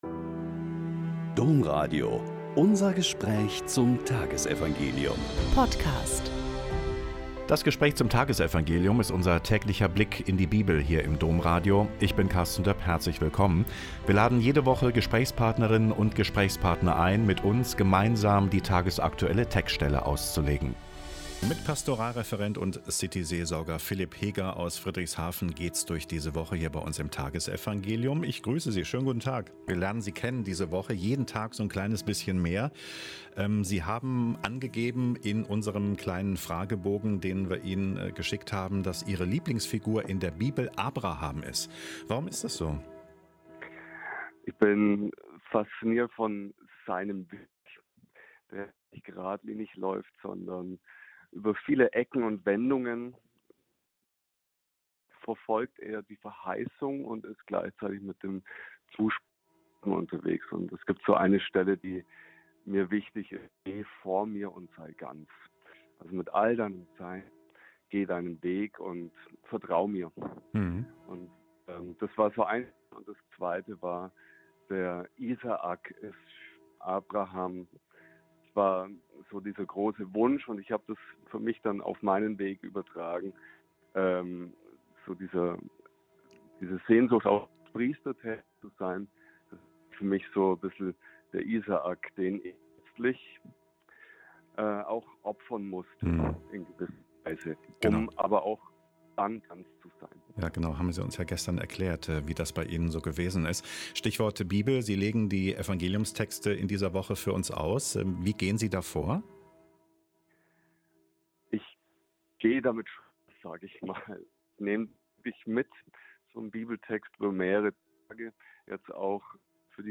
Lk 7,36-50 - Gespräch